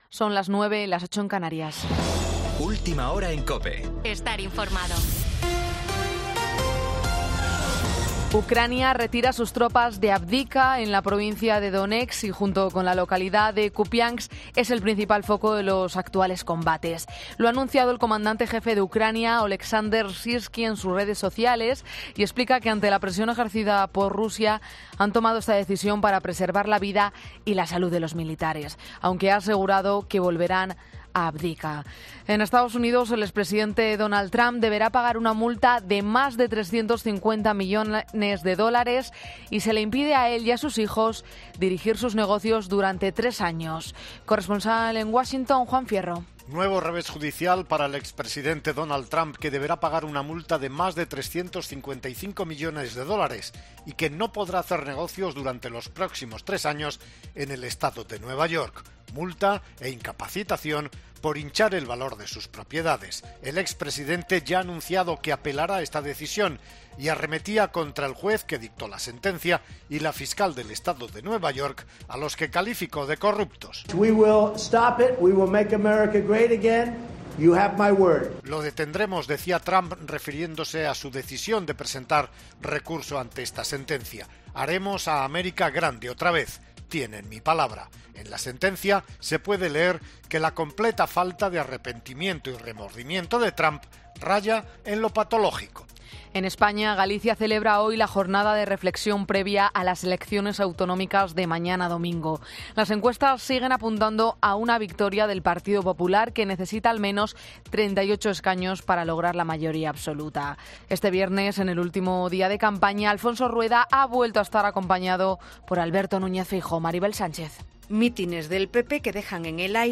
Boletín 09.00 horas del 17 de febrero de 2024